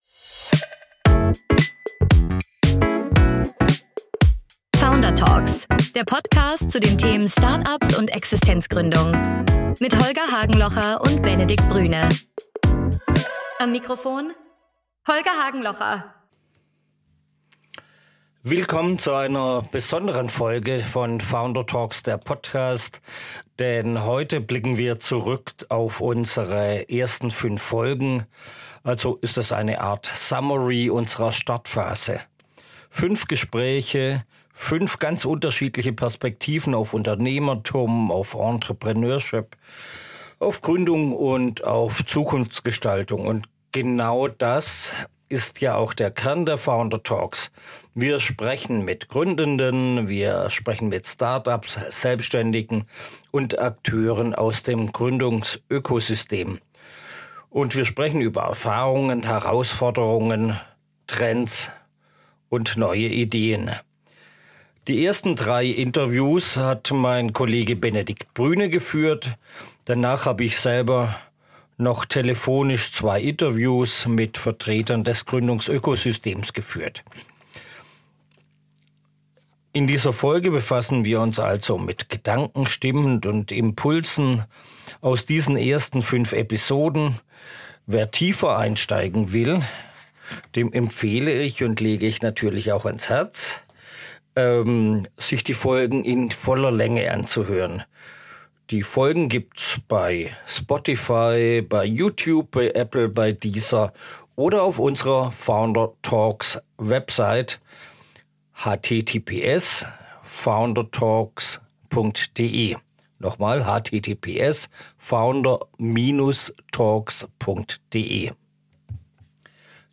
Genau das wird in den fünf ausgewählten Gesprächsausschnitten deutlich: von der Trauerrednerin, die Abschiede persönlicher gestalten will, über ein Startup zur Professionalisierung von Ausbildung bis hin zu einem digitalen Stadtführer, regionaler Wirtschaftsförderung und der Frage, wie Unternehmensnachfolge den Mittelstand sichert.